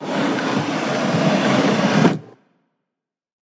卧室里传来的声音 " 抽屉打开 ( 冻结 )
描述：在Ableton中录制并略微修改的声音